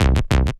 TI98BASS2C-L.wav